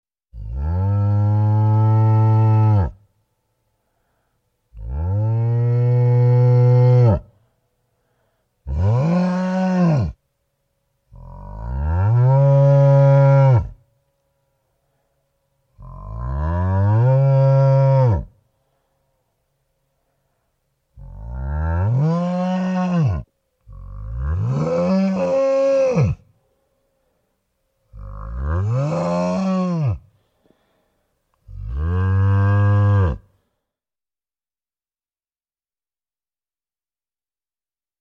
Ужасы звуки скачать, слушать онлайн ✔в хорошем качестве